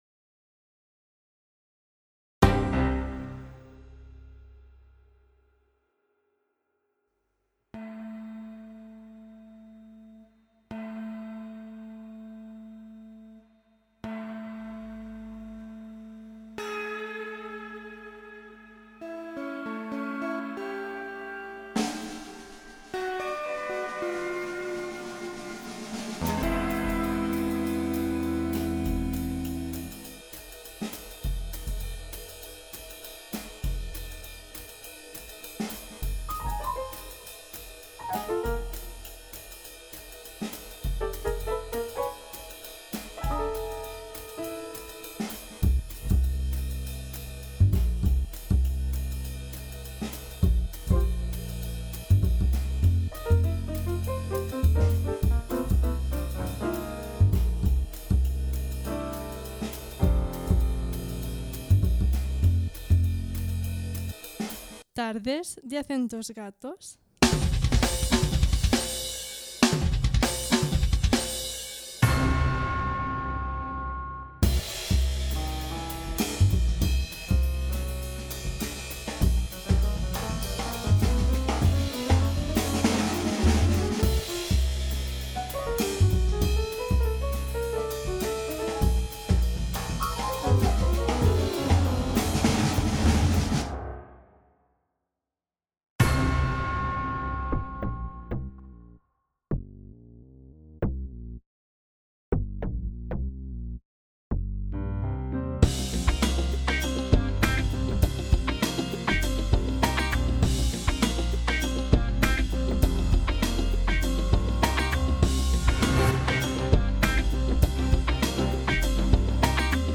Con las voces